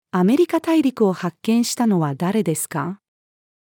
アメリカ大陸を発見したのは誰ですか？-female.mp3